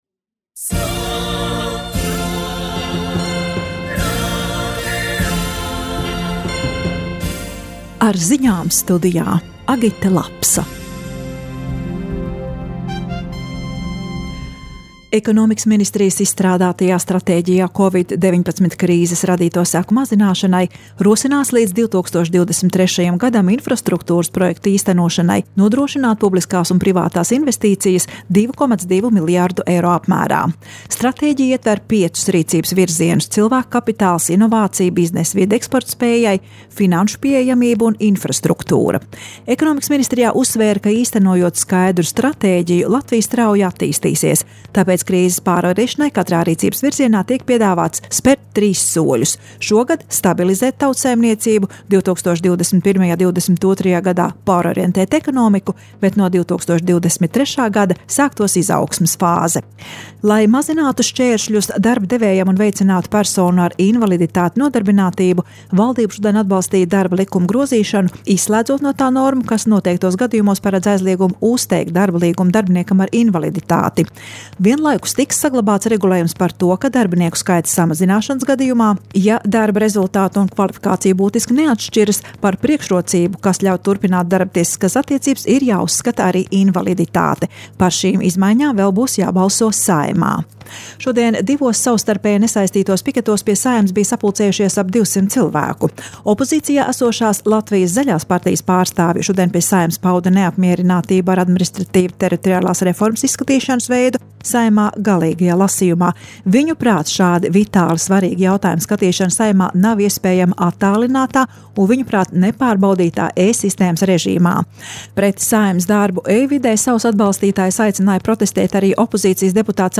Radio Skonto Dienas Ziņas 26.05.